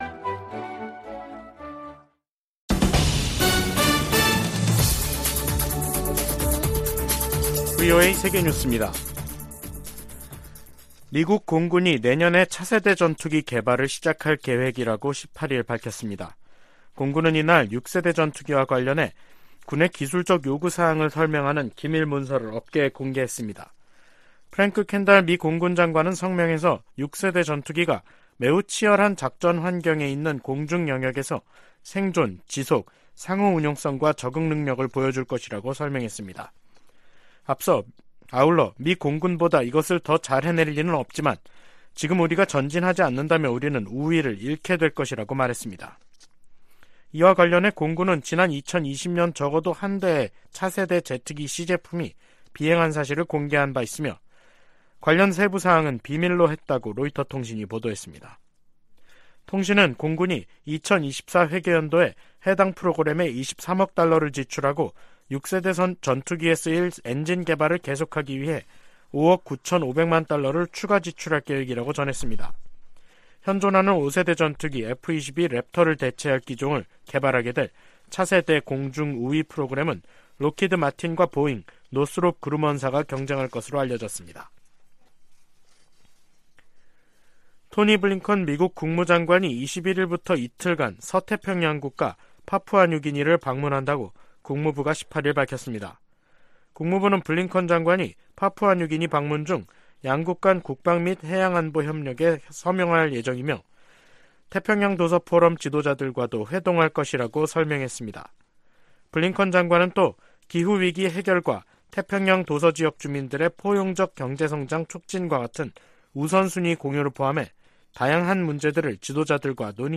VOA 한국어 간판 뉴스 프로그램 '뉴스 투데이', 2023년 5월 19일 3부 방송입니다. 미국과 일본 정상이 히로시마에서 회담하고 북한의 핵과 미사일 문제 등 국제 현안을 논의했습니다. 윤석열 한국 대통령이 19일 일본 히로시마에 도착해 주요7개국(G7) 정상회의 참가 일정을 시작했습니다. 북한이 동창리 서해발사장에 새로짓고 있는 발사대에서 고체연료 로켓을 시험발사할 가능성이 높다고 미국 전문가가 분석했습니다.